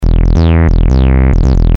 Synthetic Sound 1
Synth 2.mp3